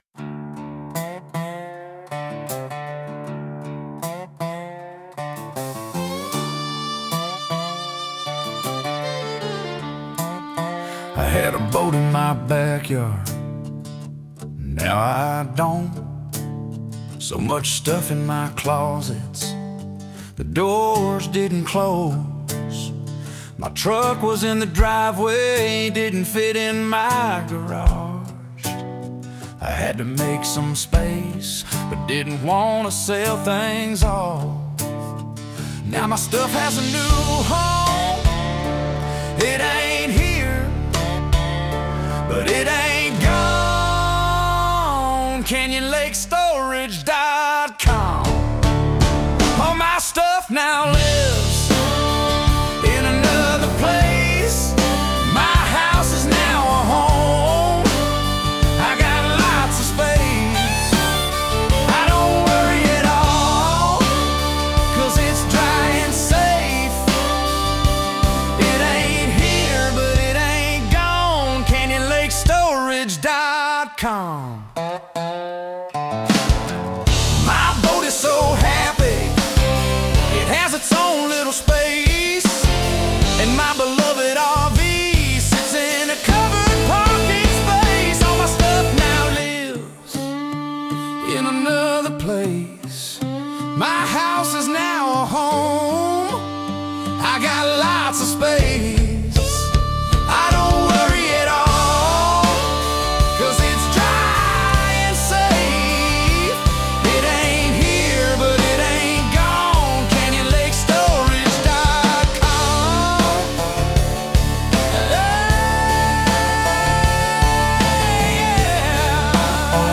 CLS-SONG-Country-Perfect.mp3